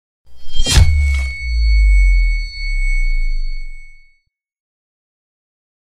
Âm thanh Rút Lưỡi Dao sắc bén ra khỏi vỏ bọc